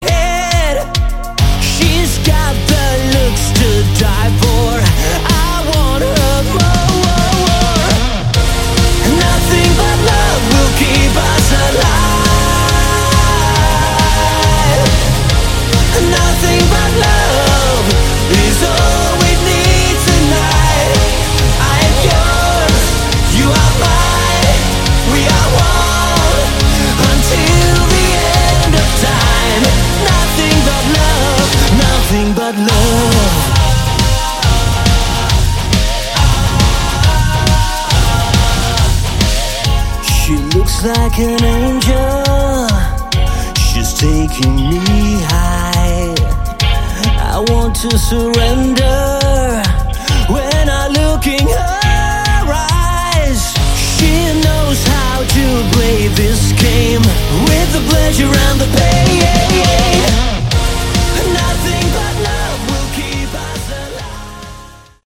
Category: Hard Rock
guitar, keyboards
vocals
bass
drums